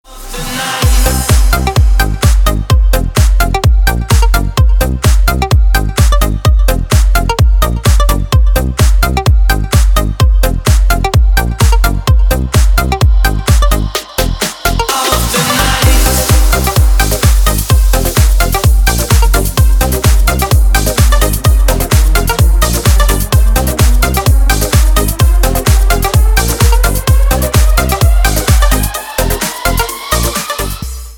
Бодрая музыка на рингтон - Dance
Категория: Dance рингтоны